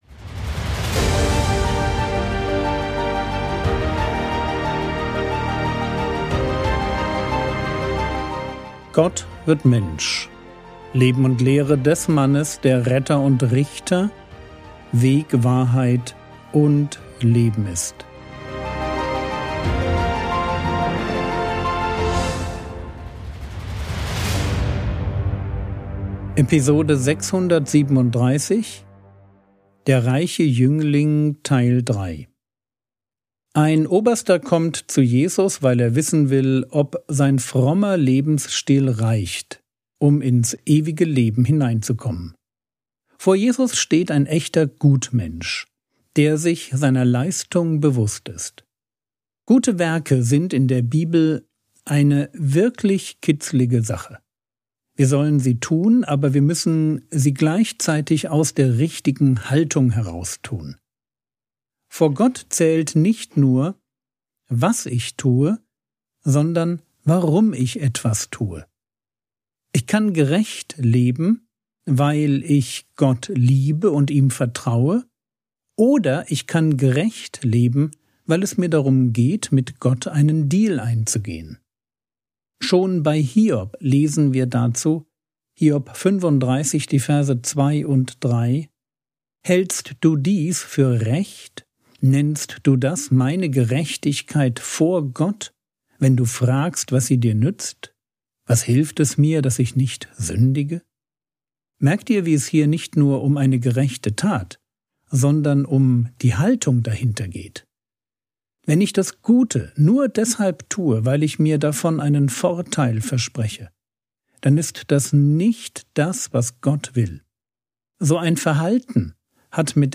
Episode 637 | Jesu Leben und Lehre ~ Frogwords Mini-Predigt Podcast